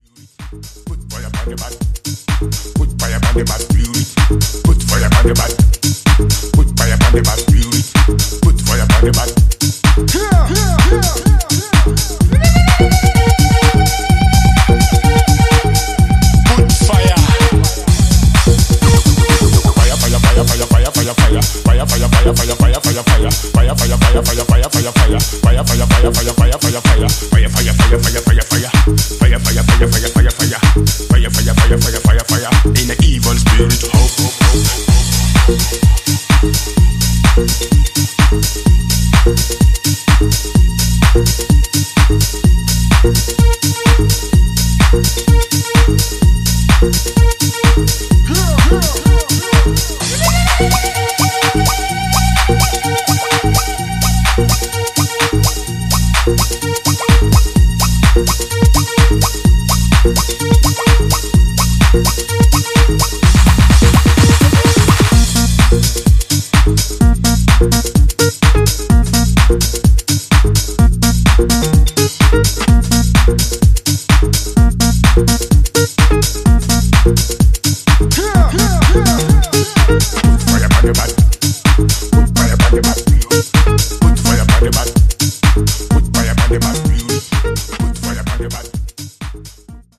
オールドスクール・レイヴにユーモラスかつモダンなセンスを塗したレーベルのカラーを体現する、即戦力曲揃いとなりました。